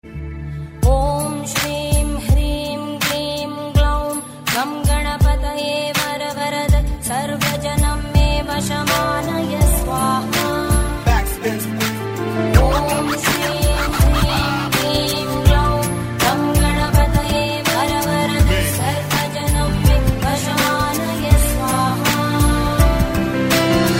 File Type : Bhajan mp3 ringtones